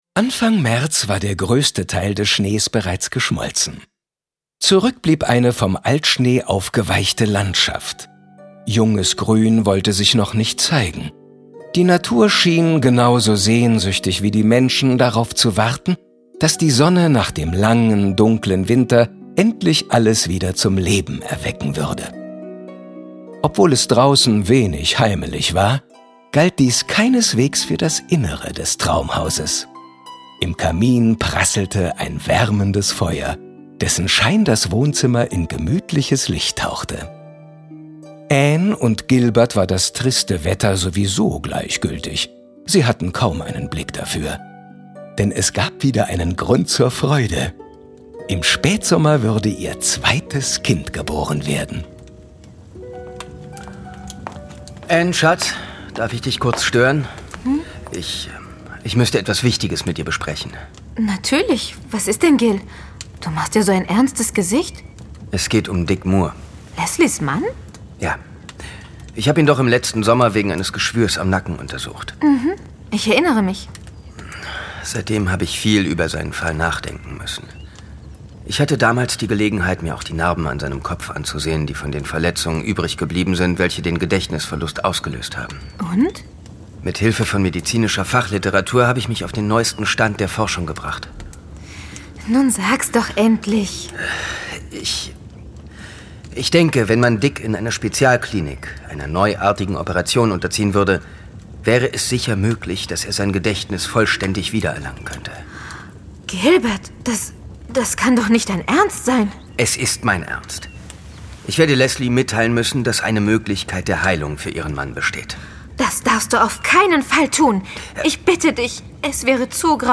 Hörspiel.